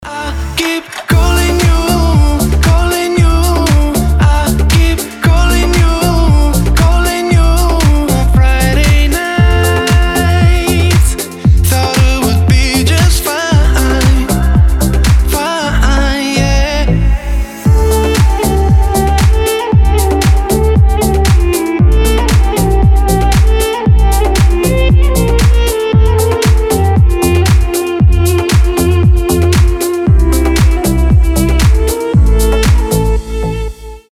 поп
восточные мотивы
dance